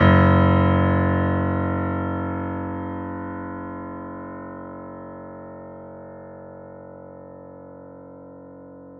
pianoSounds